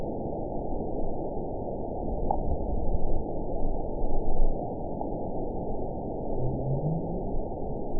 event 911956 date 03/13/22 time 17:39:12 GMT (3 years, 2 months ago) score 9.58 location TSS-AB03 detected by nrw target species NRW annotations +NRW Spectrogram: Frequency (kHz) vs. Time (s) audio not available .wav